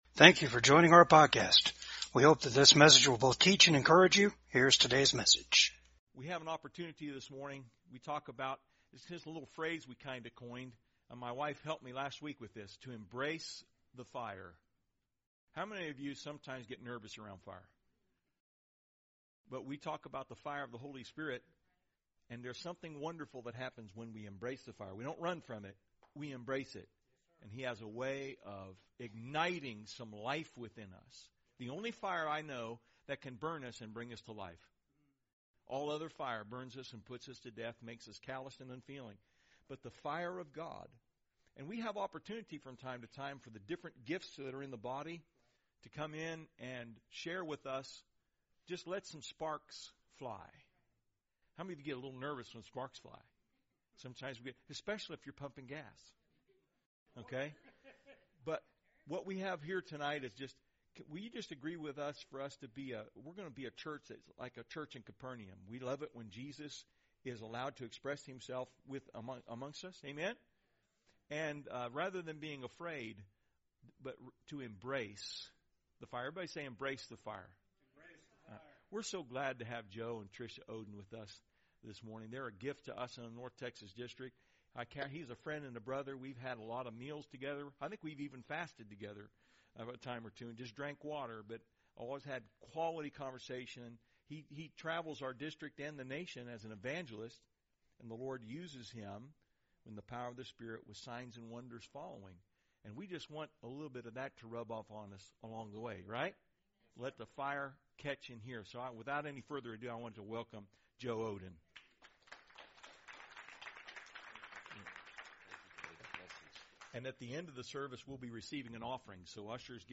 Jude 3-4 Service Type: REFRESH SERVICE CONTEND FOR THE CORE OF THE GOSPEL!